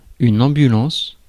Ääntäminen
Ääntäminen France: IPA: [ɑ̃.by.lɑ̃s] Haettu sana löytyi näillä lähdekielillä: ranska Käännös Substantiivit 1. ambulanco 2. malsanulveturilo Suku: f .